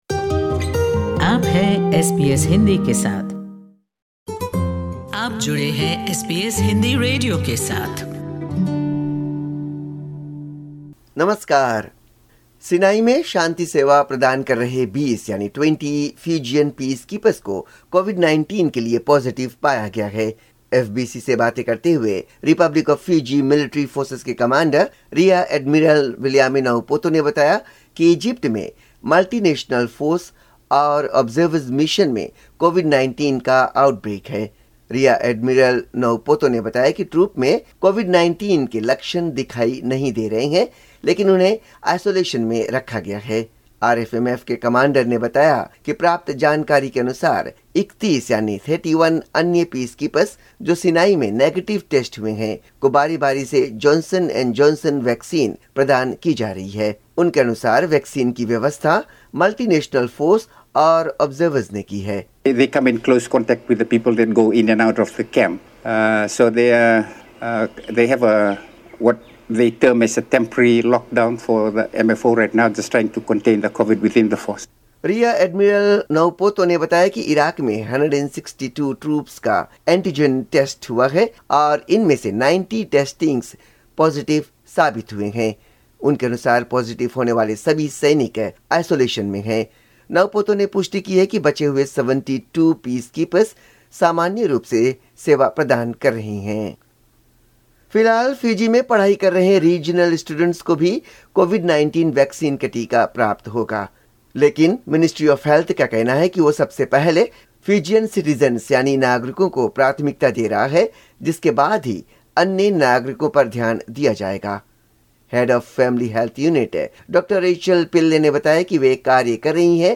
Report from Fiji in Hindi